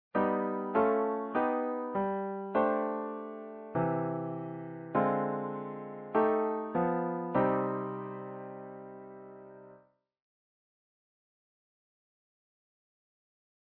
Whole tone flavored chord voicings.
Here's the first of two common shapes, thinking 'G'7+5 into 'C' major and minor.